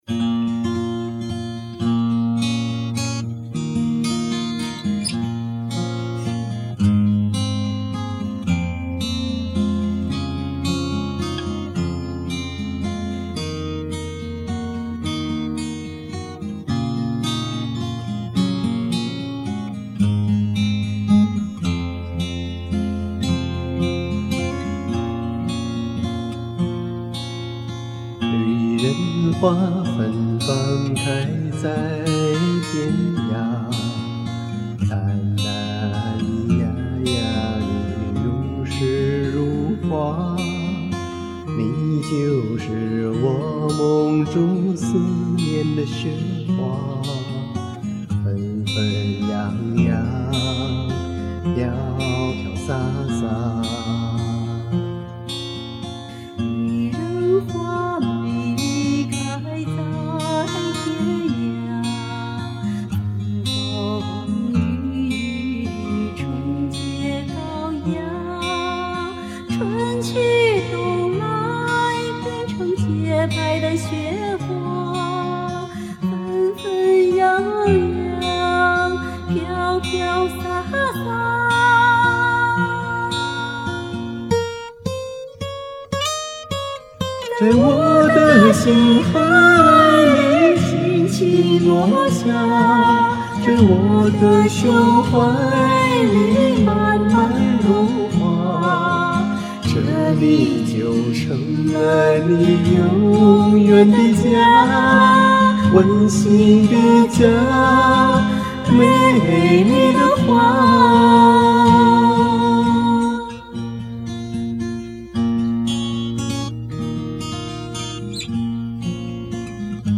《女人花》你唱的温柔细腻，深情美丽，谢谢你淋漓尽致的演绎，